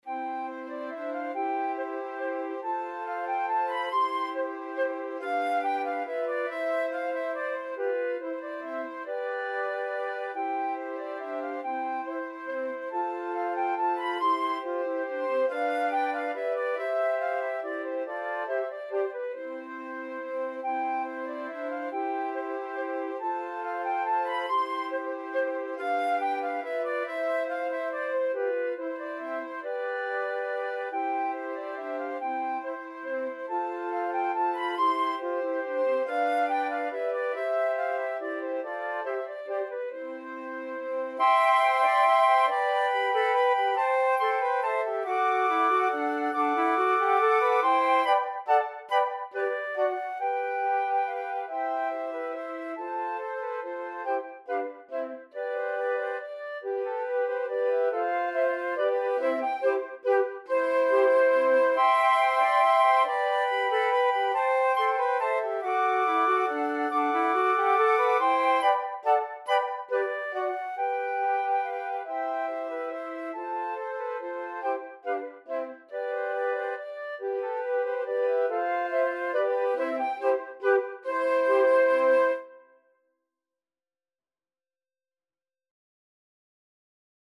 Free Sheet music for Flute Quartet
C major (Sounding Pitch) (View more C major Music for Flute Quartet )
3/4 (View more 3/4 Music)
Molto allegro = c. 140
Flute Quartet  (View more Easy Flute Quartet Music)
Classical (View more Classical Flute Quartet Music)